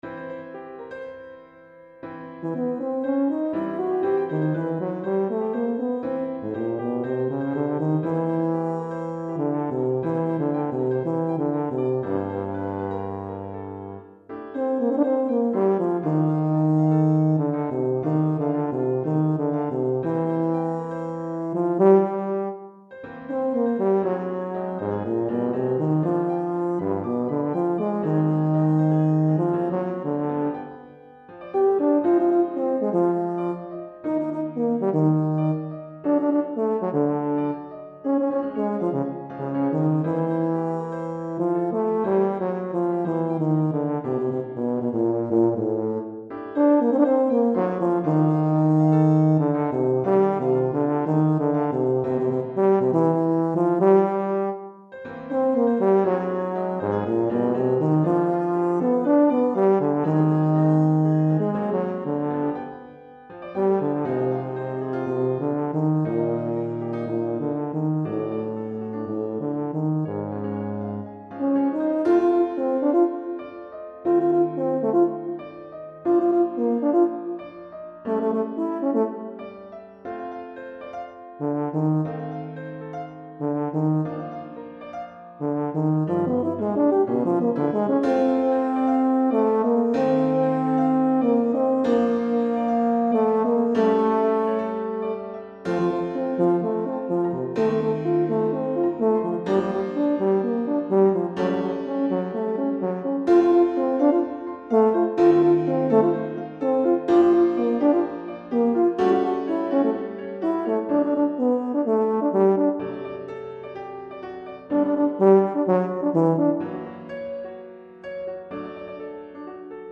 Euphonium et Piano